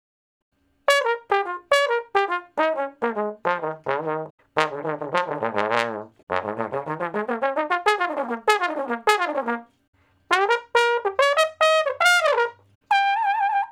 090 Bone Slo Blus 05 (Bb IV, IV, I, I).wav